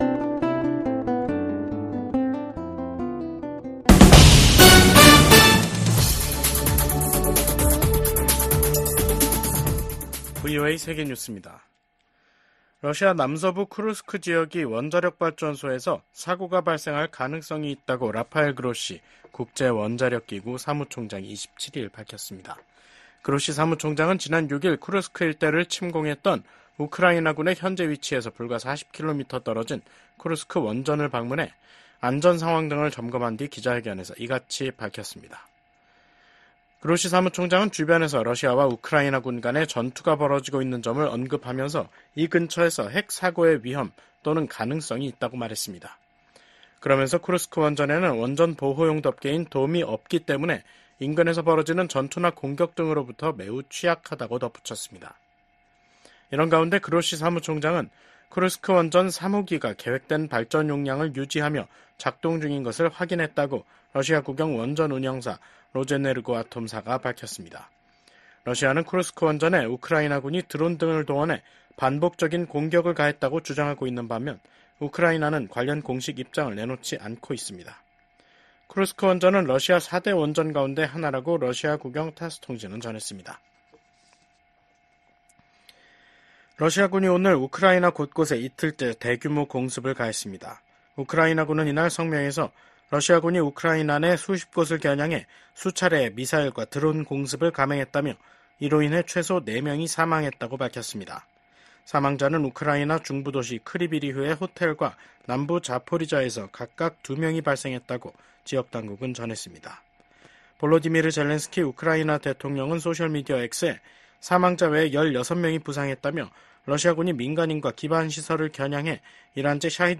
VOA 한국어 간판 뉴스 프로그램 '뉴스 투데이', 2024년 8월 27일 3부 방송입니다. 북한이 영변 핵 시설을 가동하고 평양 인근 핵 시설인 강선 단지를 확장하고 있다고 국제원자력기구(IAEA)가 밝혔습니다. 미국 정부가 한국에 대한 아파치 헬기 판매가 안보 불안정을 증대시킬 것이라는 북한의 주장을 일축했습니다. 북한이 새 ‘자폭용 무인기’를 공개한 가운데 미국의 전문가들은 미사일보다 저렴한 비용으로 한국의 방공망을 위협할 수 있다고 우려했습니다.